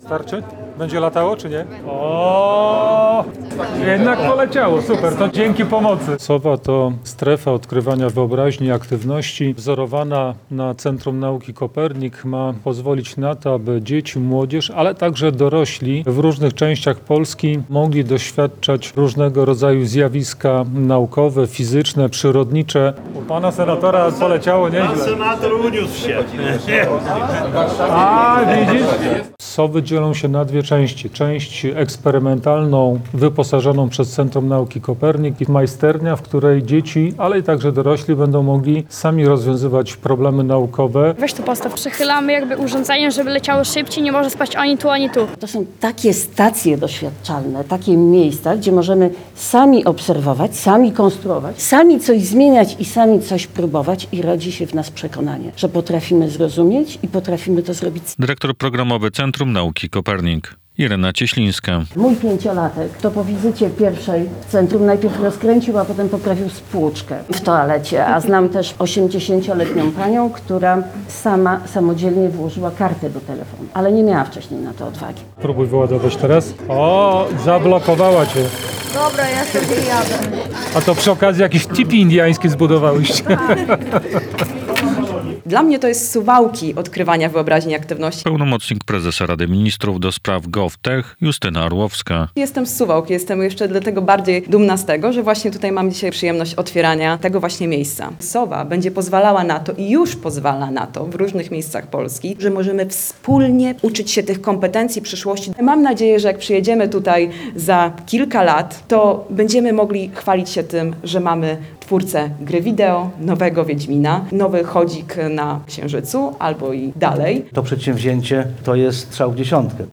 Czy w Suwałkach powstało miejsce, gdzie pasją do nauki zarażą się przyszli twórcy gier komputerowy i projektanci pojazdów kosmicznych? - relacja
Urządzenia pojawiły w suwalskim Parku Naukowo-Technologicznym, gdzie w poniedziałek (09.05), dzięki wsparciu finansowemu Ministerstwa Edukacji i Nauki, otwarto Strefę Odkrywania, Wyobraźni i Aktywności.